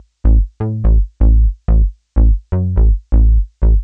cch_bass_loop_junes_125_A.wav